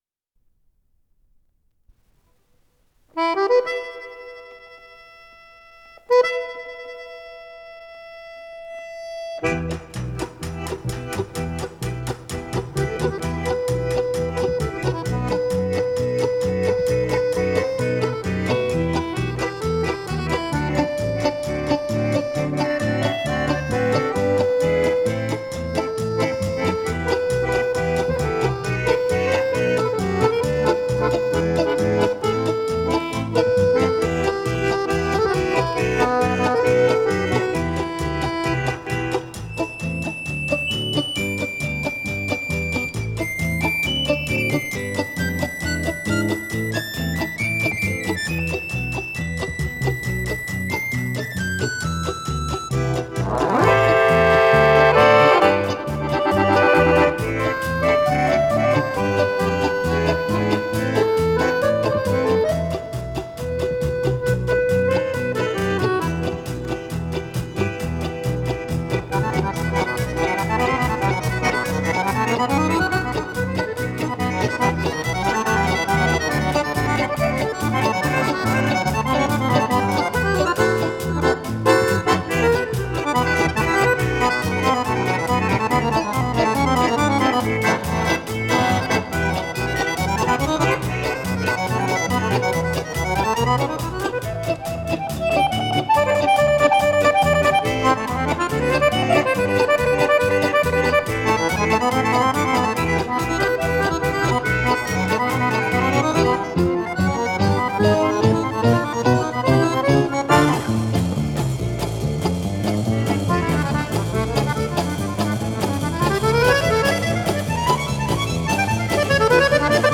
с профессиональной магнитной ленты
ПодзаголовокЭстрадная миниатюра
АккомпаниментИнструментальное трио